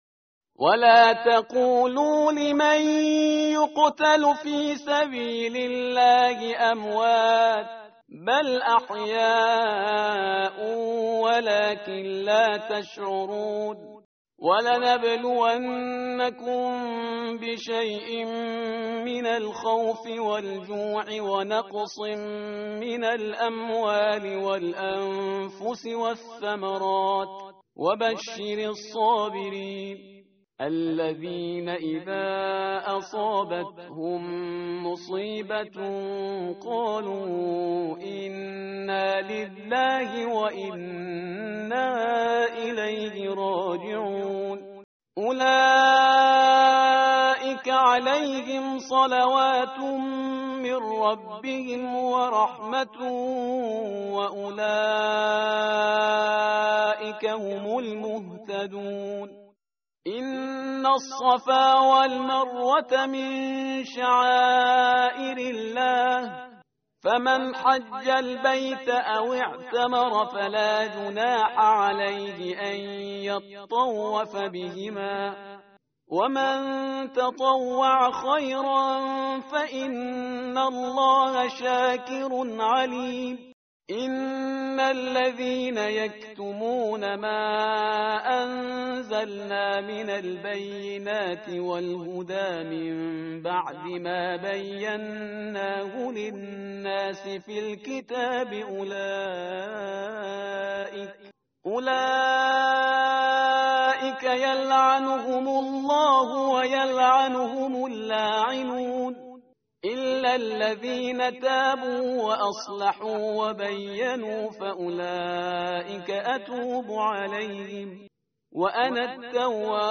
tartil_parhizgar_page_024.mp3